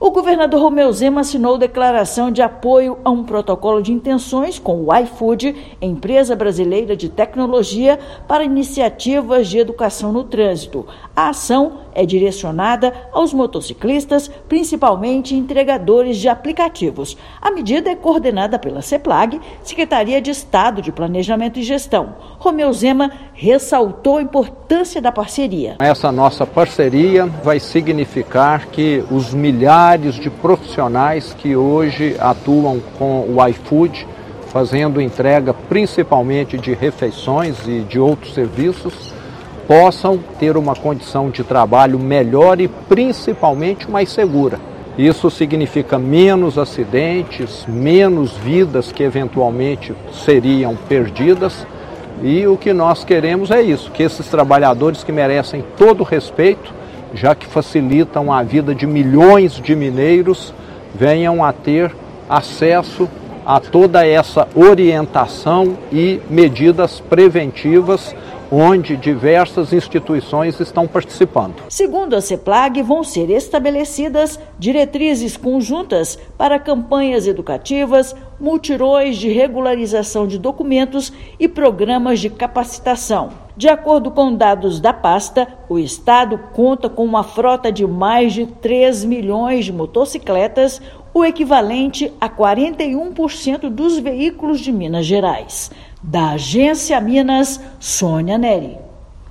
Estado declara apoio a parceria com a empresa, em ação que integra Semana Nacional de Trânsito e reforça compromisso com a preservação de vidas no tráfego. Ouça matéria de rádio.